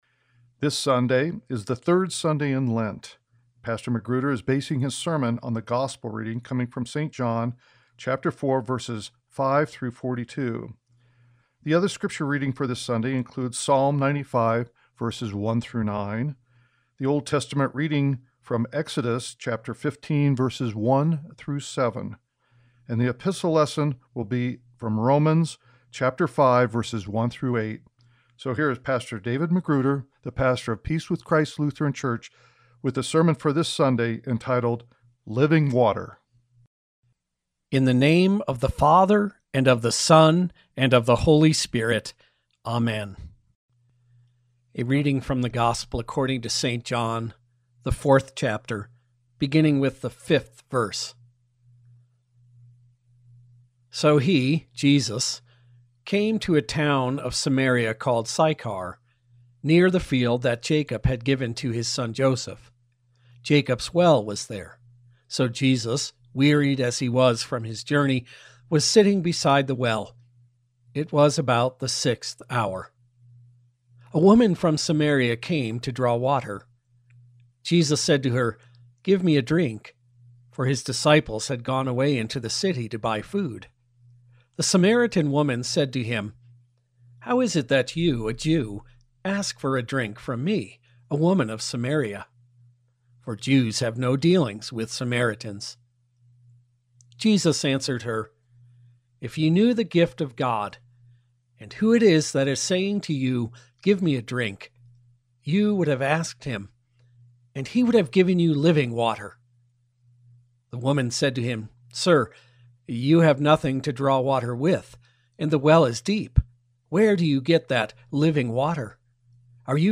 Living Water - Sermon At Peace With Christ Lutheran Church